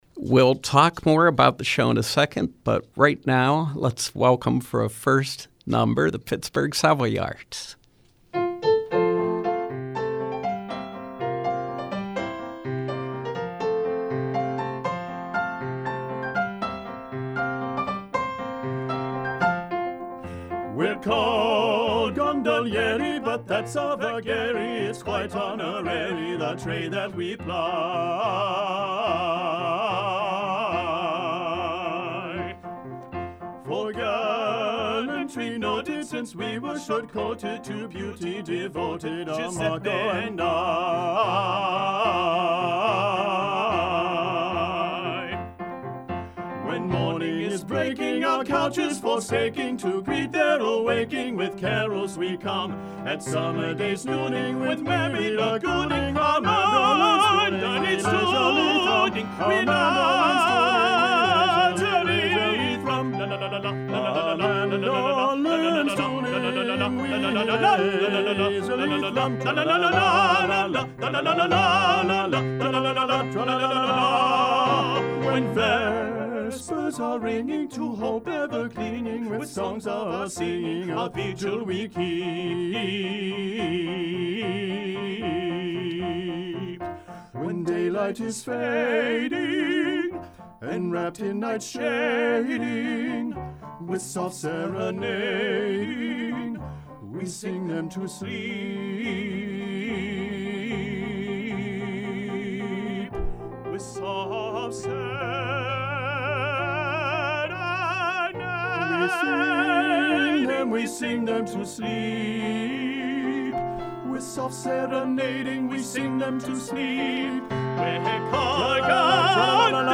Live Music: Pittsburgh Savoyards